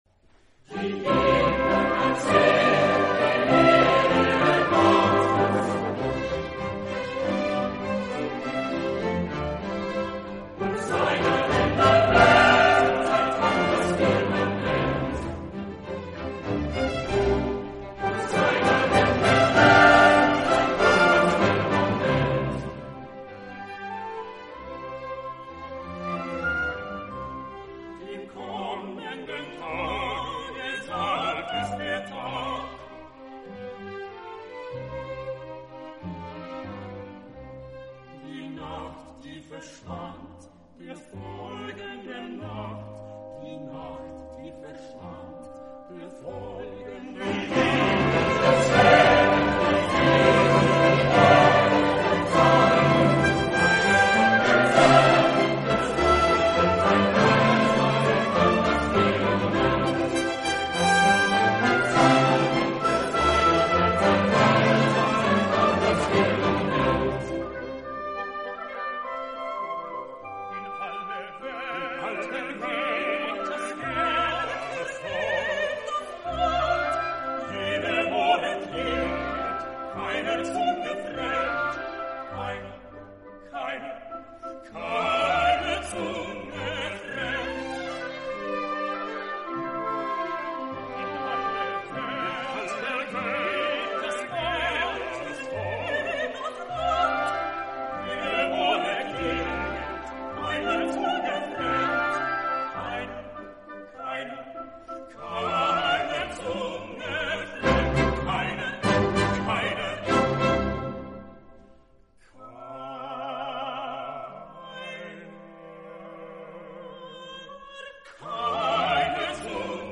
ebullient spirit
oratorio